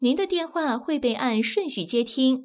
ivr-call_answered_order_received.wav